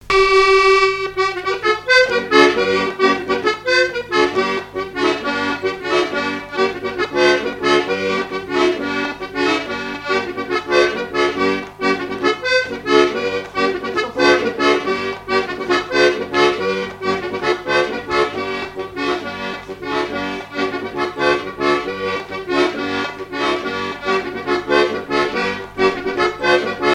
Chants brefs - A danser
accordéon diatonique
Pièce musicale inédite